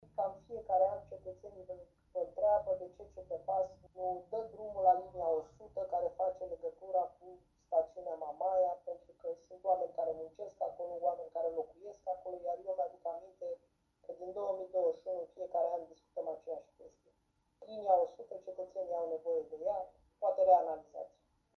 Interpelări la începutul și finalul ședinței Consiliului Local Constanța.
La finalul ședinței, consilierul independent Felicia Ovanesian le-a cerut reprezentanților municipalității să prelungească traseul liniei 100 până în stațiunea Mamaia.